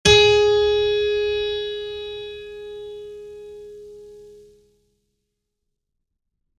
piano-sounds-dev
gs3.mp3